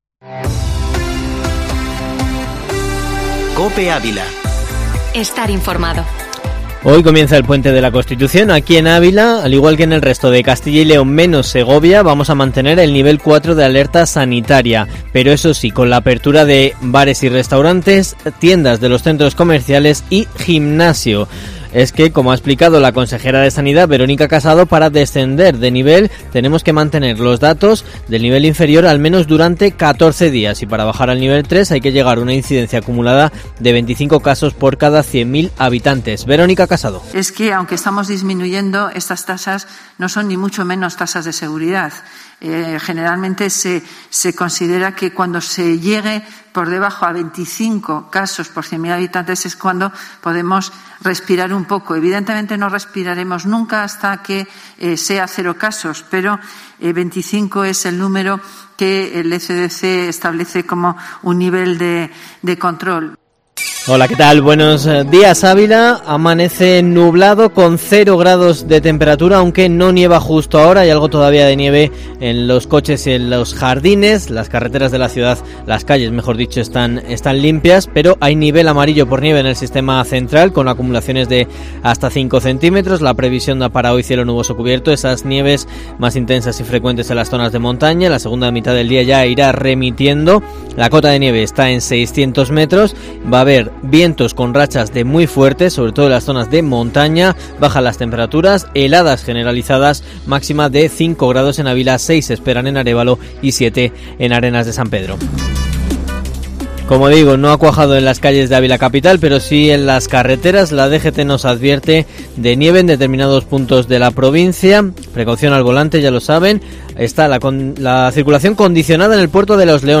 Informativo matinal Herrera en COPE Ávila 04/12/2020